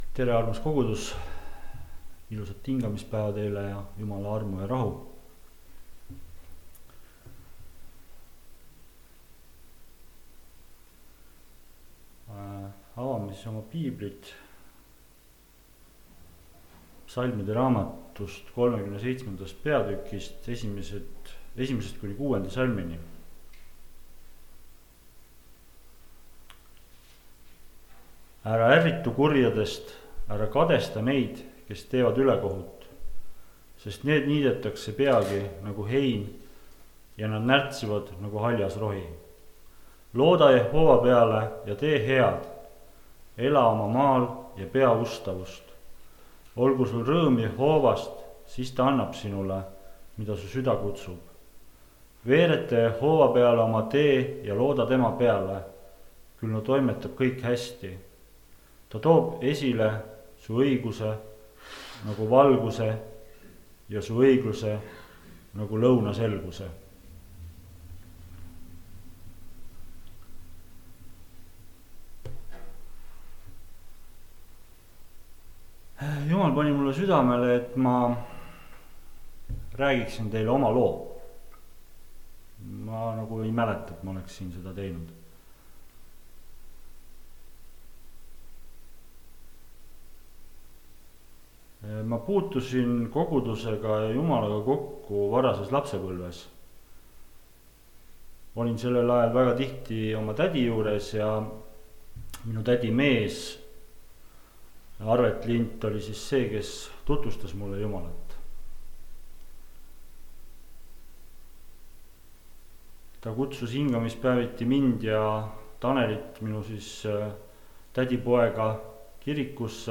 kategooria Audio / Jutlused / Teised